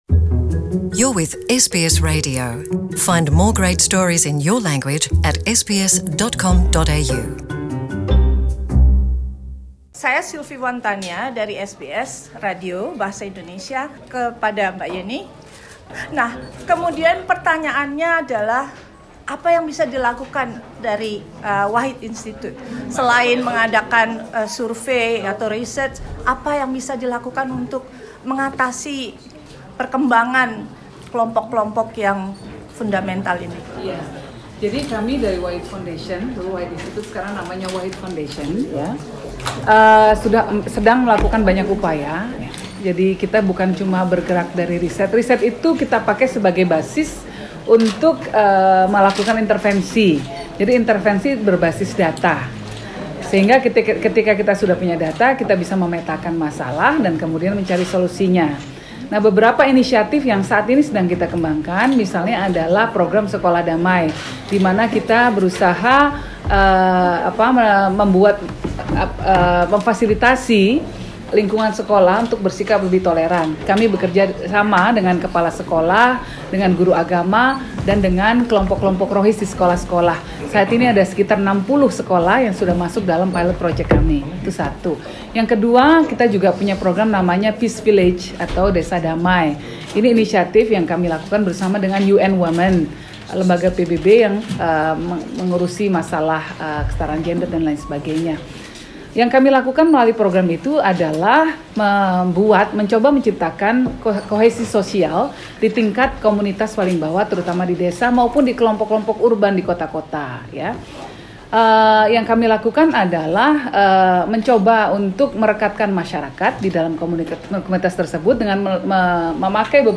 Najwa Shihab memboyong acara talkshownya, Catatan Najwa ke Melbourne dan mendiskusikan pertanyaan; bagaimana menyatukan Indonesia setelah pemilihan presiden?